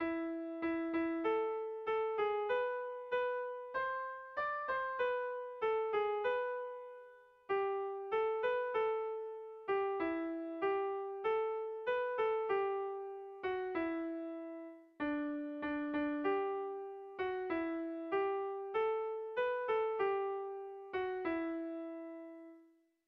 Sehaskakoa
AB